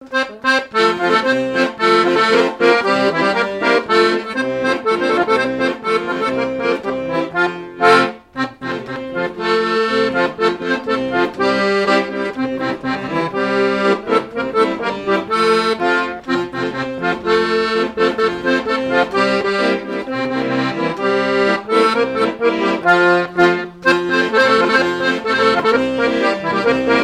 Quadrille - Le galop
danse : quadrille : galop
airs de danses issus de groupes folkloriques locaux
Pièce musicale inédite